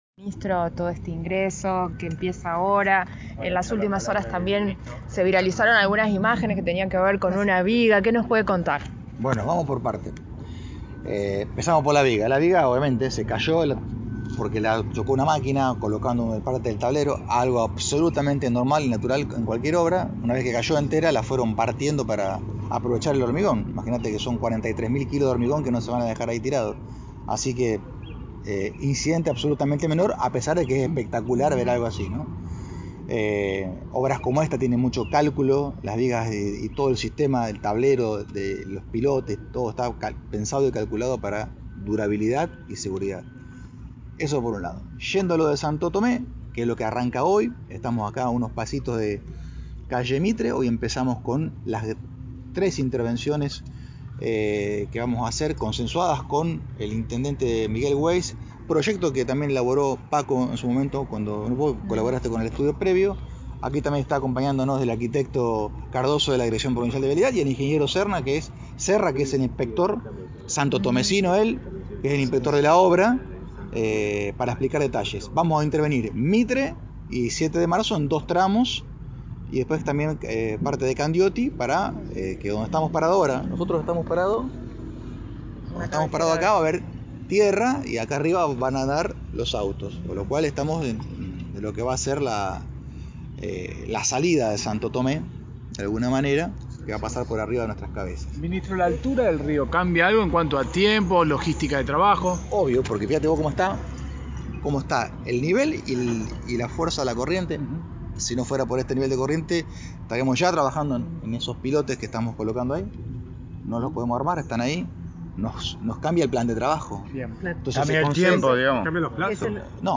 AUDIO de Lisandro Enrico – Ministro de Obras Públicas